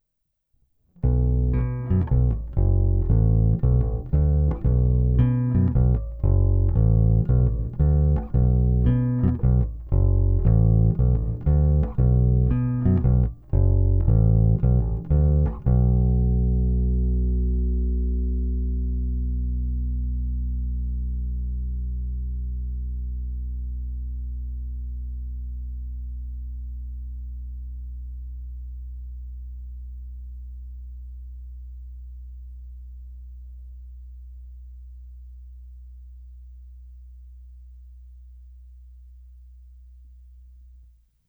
Klasický Precision, středobasový, ale umí i pěkně štěknout a možná i kousnout.
Není-li uvedeno jinak, následující nahrávky jsou provedeny rovnou do zvukovky a dále kromě normalizace ponechány bez úprav.
Tónová clona vždy plně otevřená.
Hra mezi krkem a snímačem